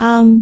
speech
syllable